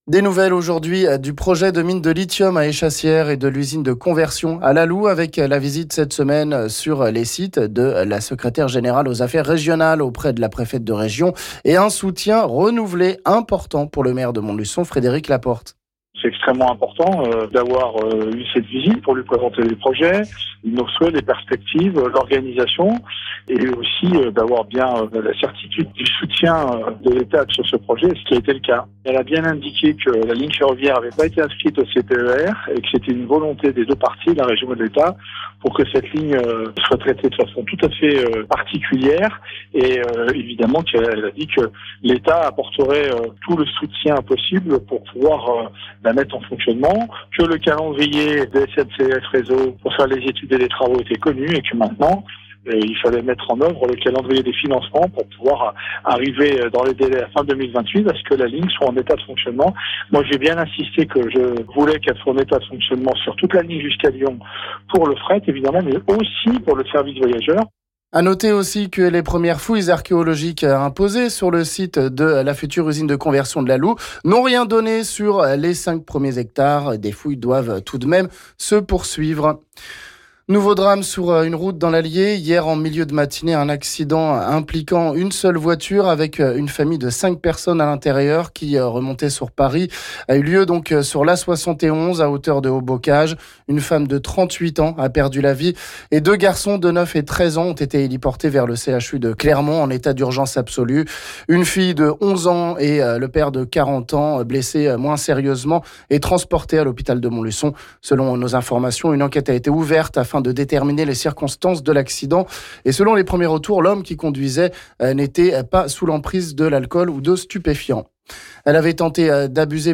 On écoute le maire de Montluçon ici...